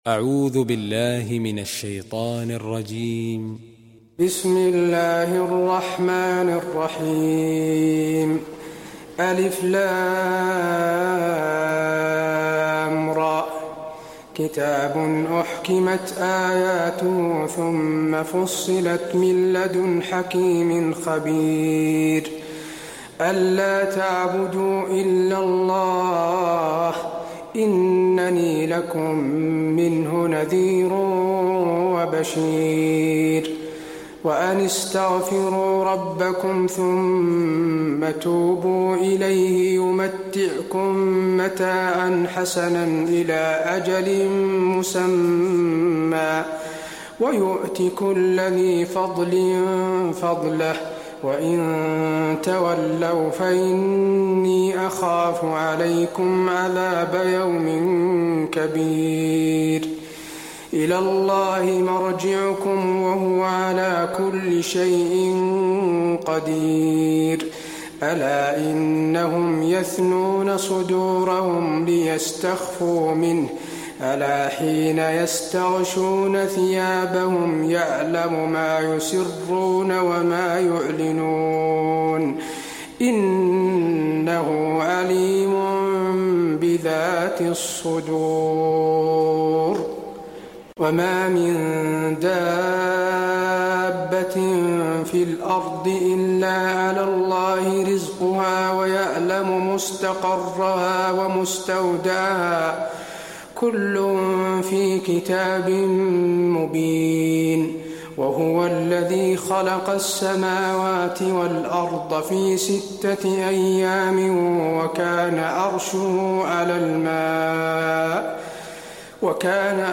المكان: المسجد النبوي هود